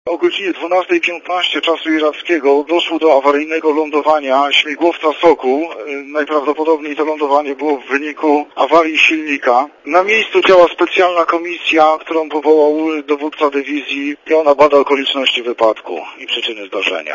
Mówi podpułkownik